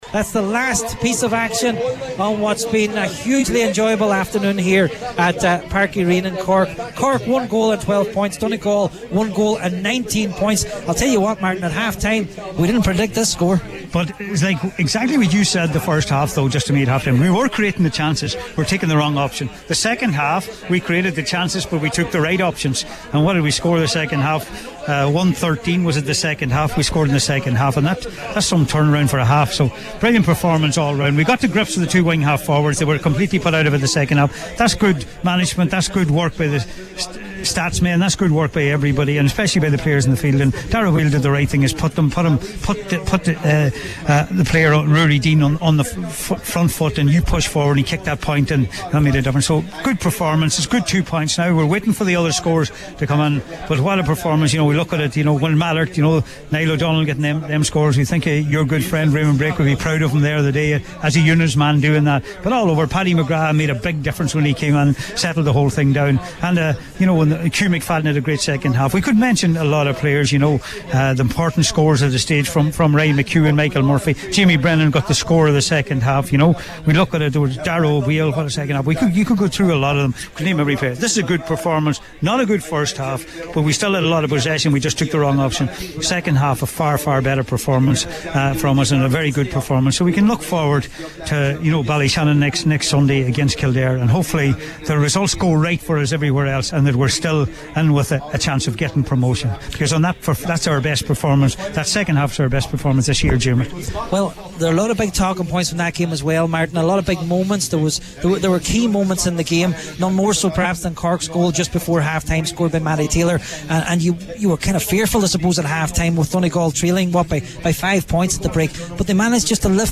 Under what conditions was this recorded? spoke at the final whistle…